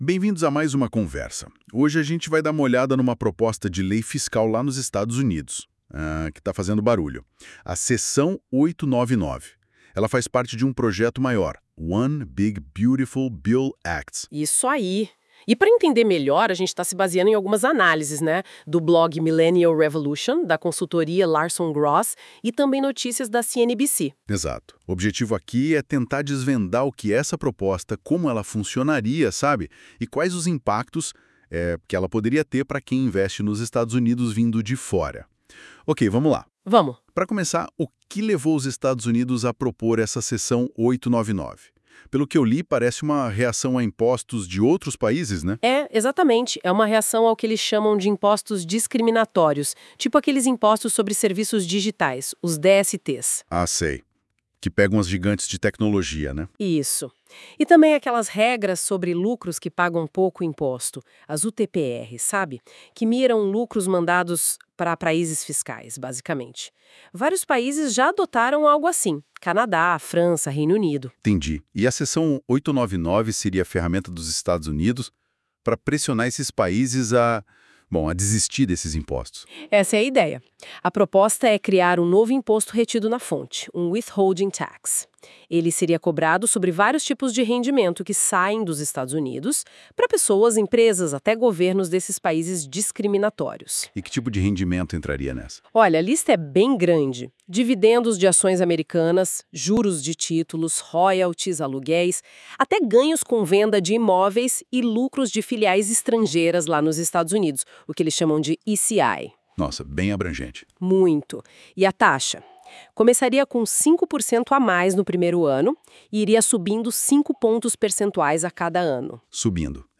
(Se quiser ouvir, colocamos um podcast gerado pelo NotebookLM abaixo – dê o play)